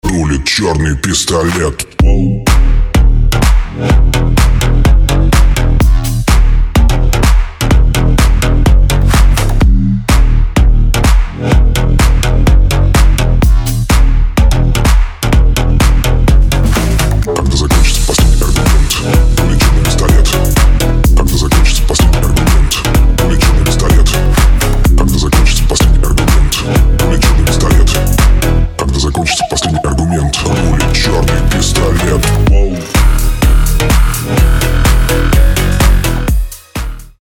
• Качество: 192, Stereo
мужской вокал
dance
club
агрессивные
в стиле gangsta